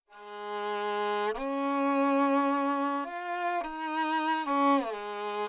2.2.3 Hybrid sound synthesis: a real player and virtual violins
This hybrid synthesis makes it possible to drive different virtual violins with the identical realistic forcing waveform (measured during real playing) so that sound differences can be compared with no complications arising from variations in playing (Audio file Violin5_HybridSynth